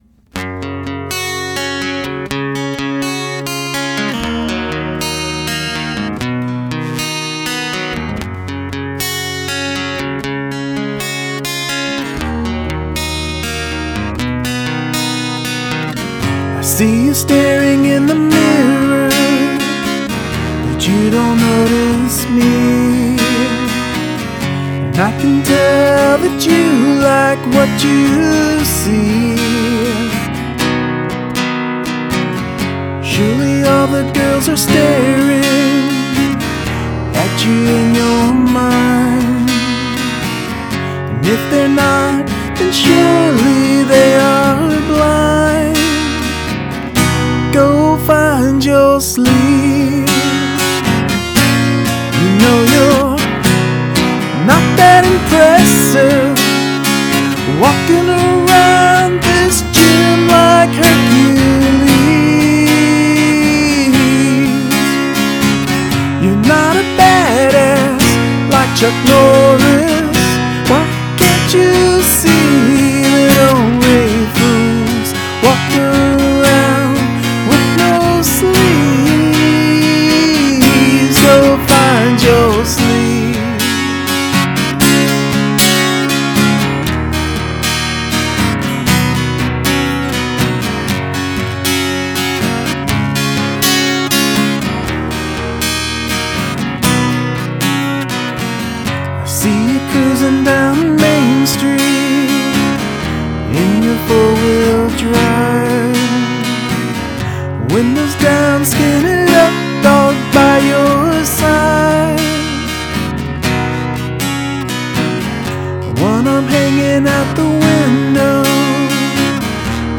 Great Song!  Made me laugh as I listened......
Great song, your voice sounds very good here.
Beautiful Delivery!